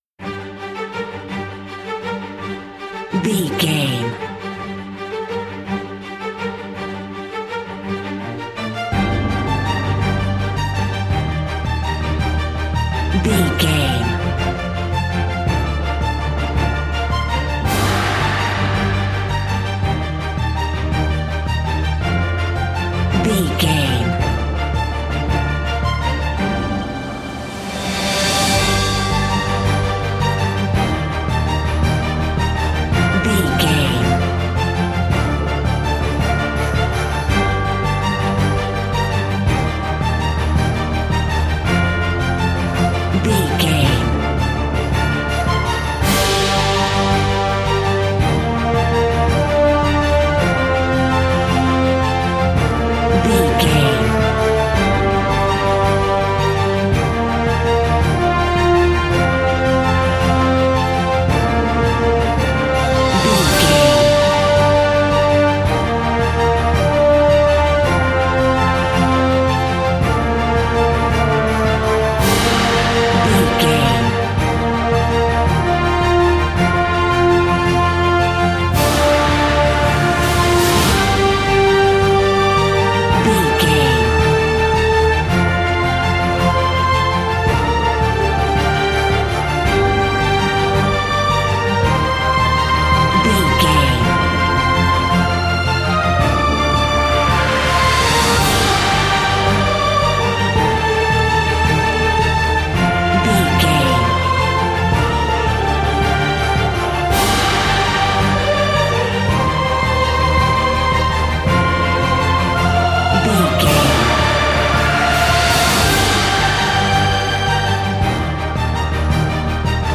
Epic / Action
Uplifting
Aeolian/Minor
heavy
powerful
brass
cello
strings
synthesizers
hybrid